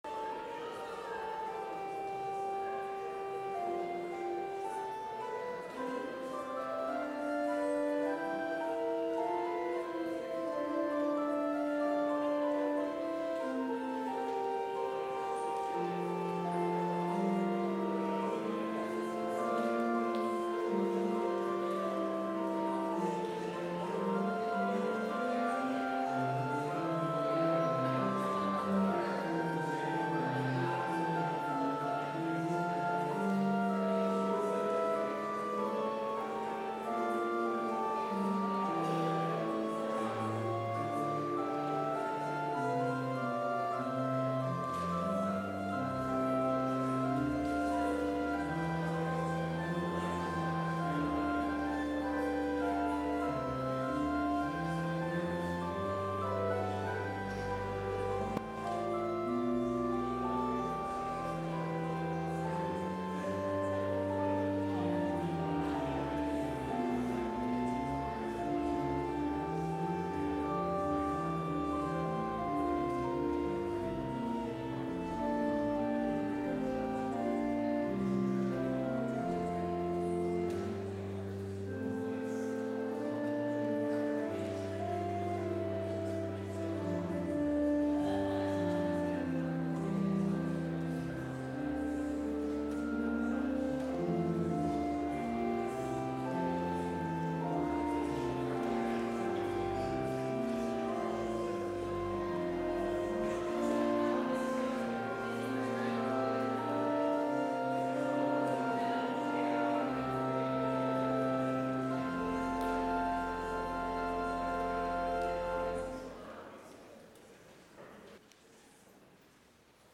Complete service audio for Chapel - Friday, April 26, 2024